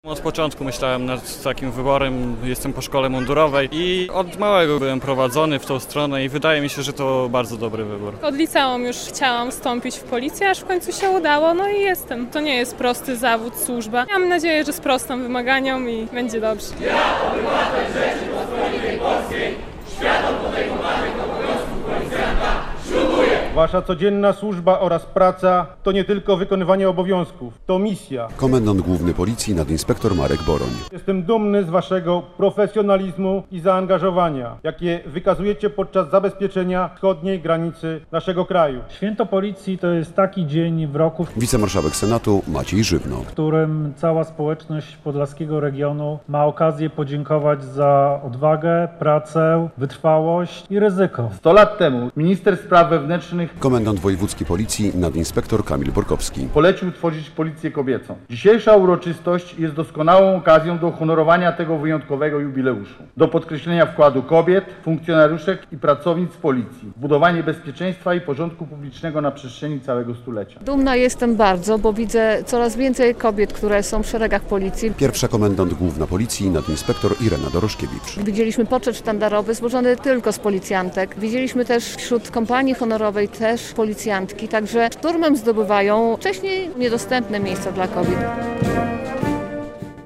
Na placu przed Pałacem Branickich odbyło się wojewódzkie Święto Policji. Funkcjonariusze świętowali 106. rocznicę powołania formacji i 100 lat kobiet w policji.